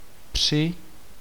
Ääntäminen
US : IPA : /əˈɹæwnd/